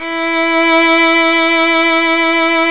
Index of /90_sSampleCDs/Roland - String Master Series/STR_Violin 1-3vb/STR_Vln2 _ marc
STR  VL E 5.wav